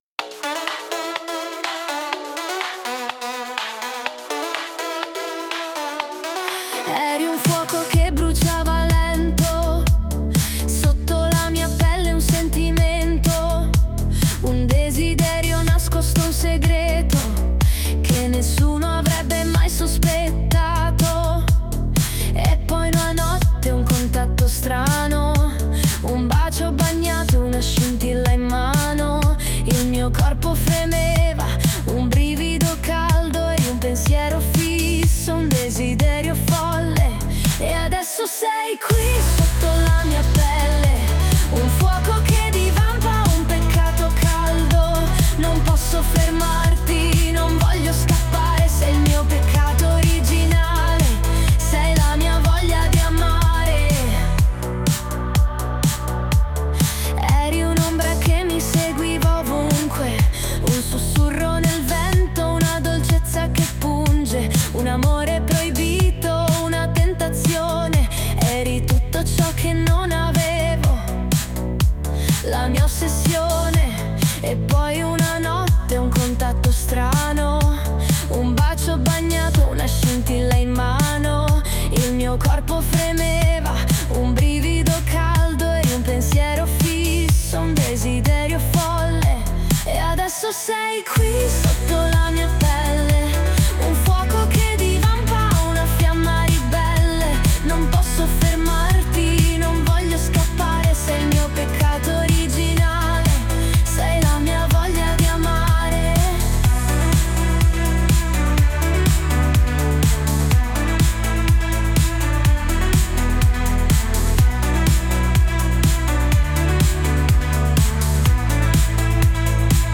Urban Pop cinematografico Ascoltaci ora su Spotify
Pop cinematografico, urban pop emotivo.
voce intensa e carismatica